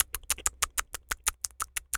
rabbit_eating_01.wav